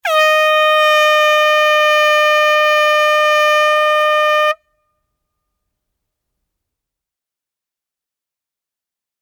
64-long-air-horn-blast-_-sound-effect-_-stadium-_-arena.mp3